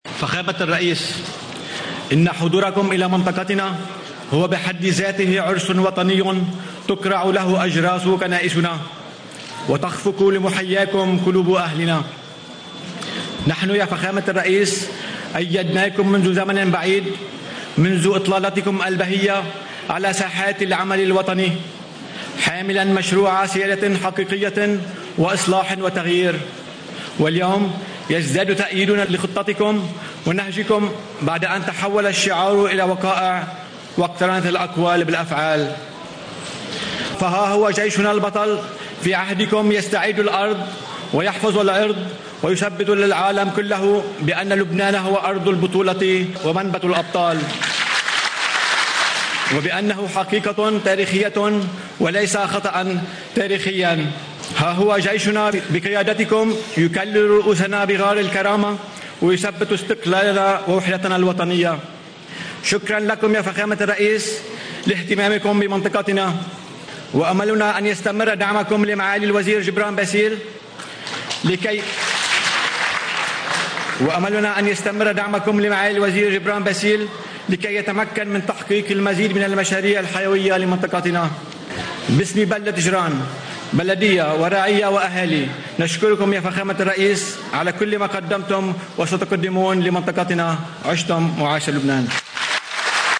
كلمة رئيس بلدية جران بسام خوري، في حفل تدشين طريق القديسين للرئيس عون: (25 آب 2017)